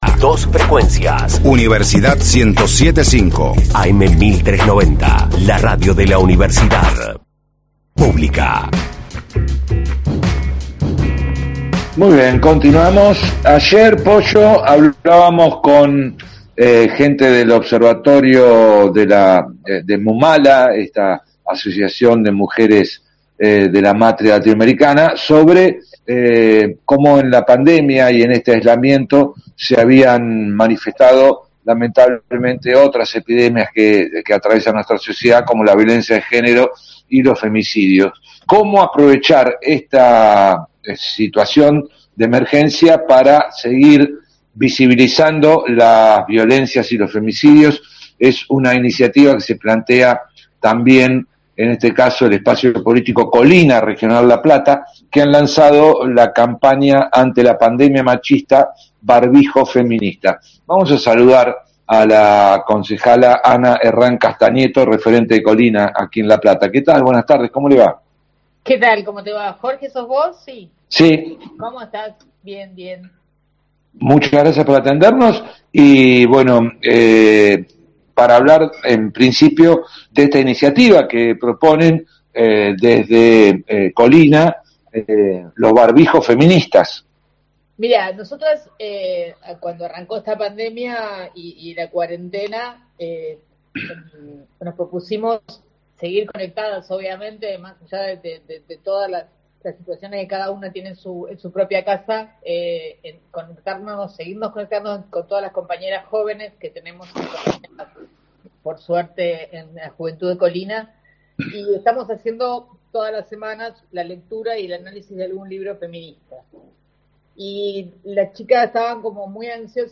Barbijo Feminista: entrevista a Ana Castagneto, de Kolina – Radio Universidad
Ana Castagneto, concejala platense y referente de Kolina, habló hoy con Radio Universidad de La Plata, acerca de la campaña “Barbijo Feminista”, para visibilizar la violencia de género, en el contexto de la crisis sanitaria por el coronavirus, en cuyo marco se dispuso la obligatoriedad del uso de estos métodos de barrera contra la Covid-19.